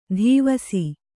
♪ dhīvasi